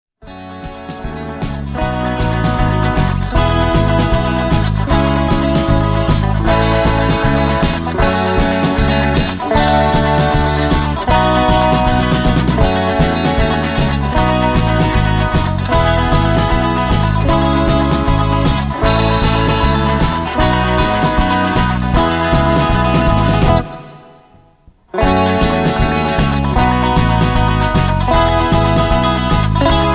rock and roll , classic rock , karaoke